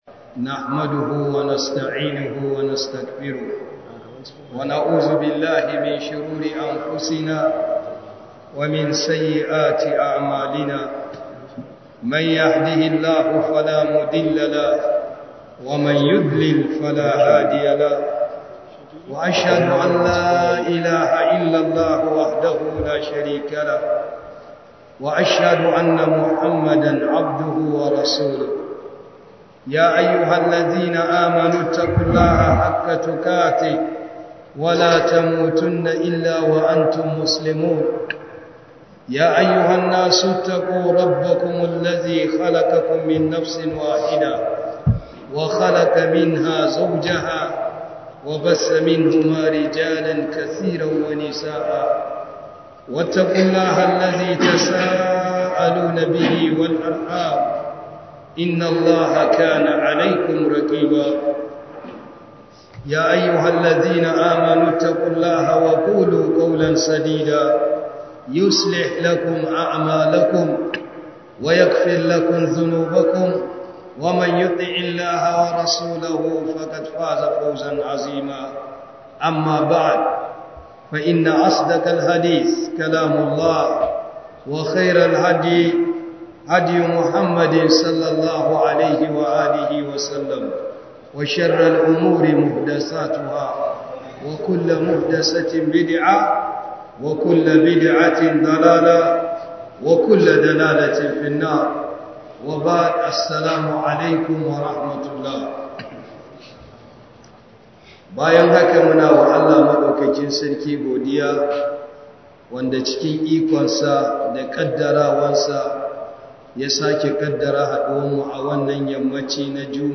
010 Prof Isa Ali Pantami Tafsir 2026